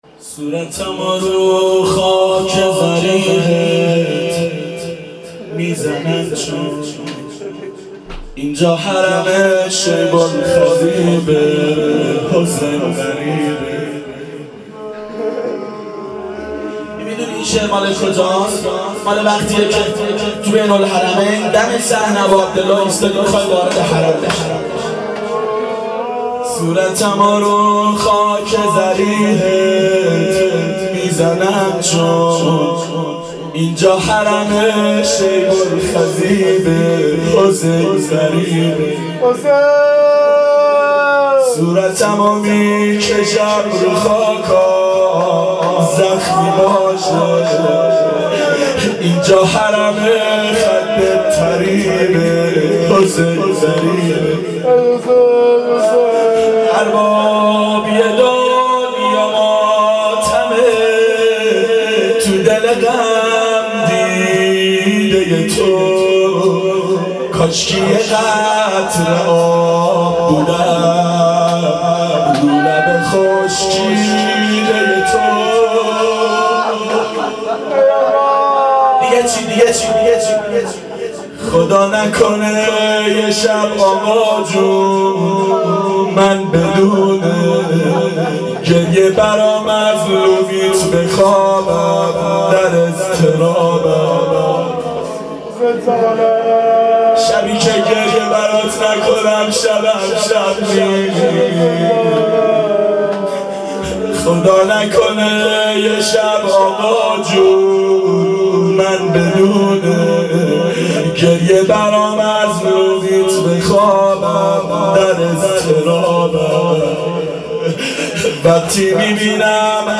• شب شهادت حضرت زهرا سلام الله علیها 1389 هیئت عاشقان اباالفضل علیه السلام